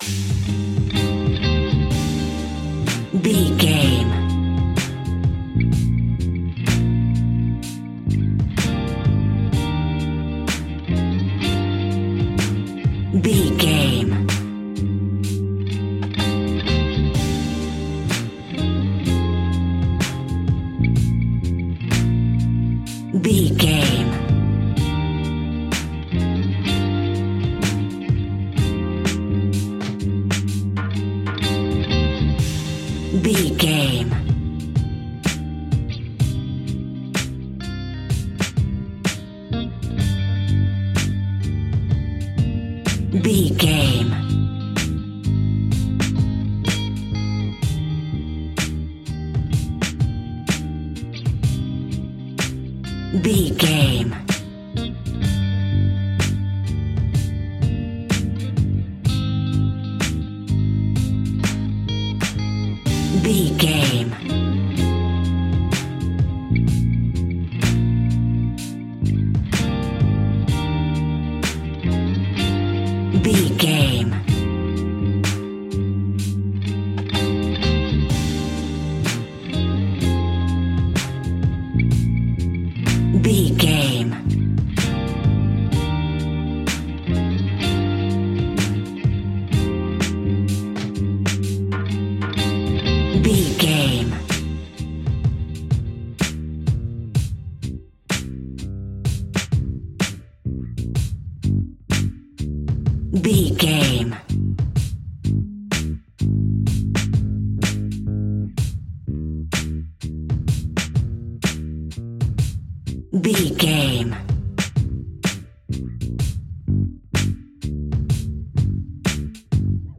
Ionian/Major
D♯
laid back
Lounge
sparse
new age
chilled electronica
ambient
atmospheric
morphing